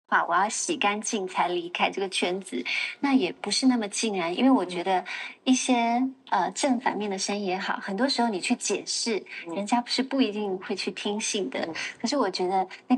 Tydlig AI-röst för GPS-navigering
Text-till-tal
Naturlig kadens
Noiz.ais AI-röst för GPS-navigering har en professionell, auktoritär men ändå vänlig ton som säkerställer att förare aldrig missar en sväng.